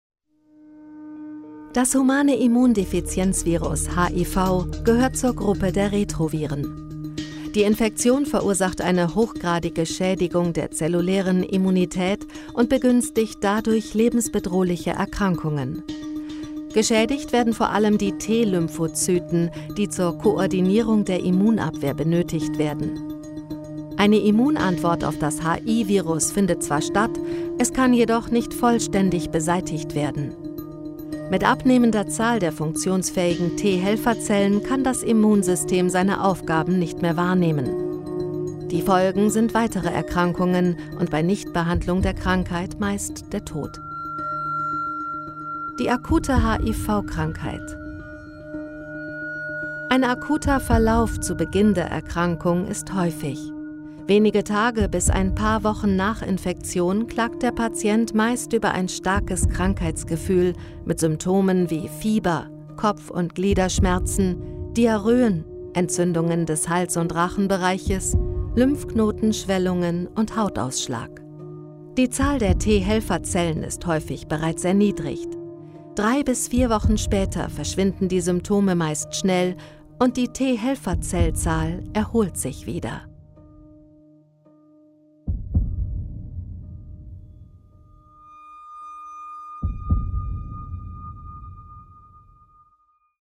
Natürlich, Cool, Vielseitig, Warm
Erklärvideo
The sound of her voice is middle-aged (about 30-50 years), warm, dynamic and changeable.